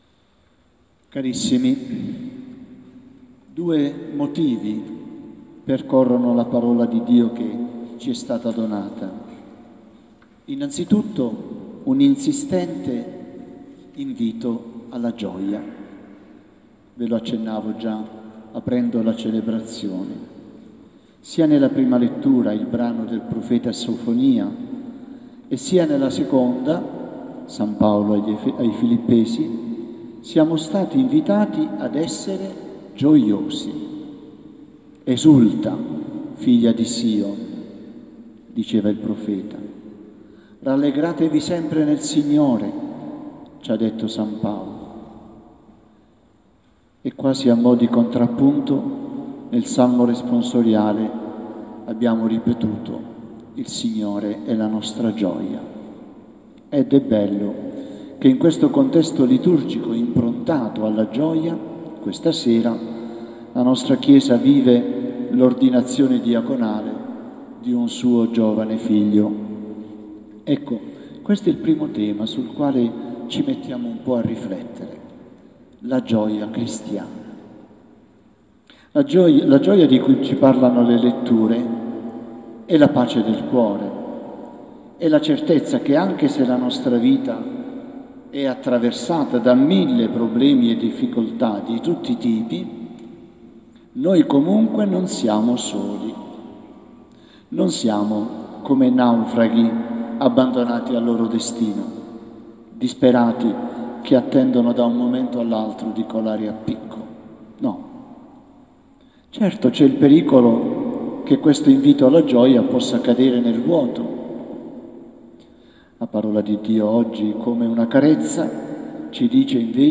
Ordinazione Diaconale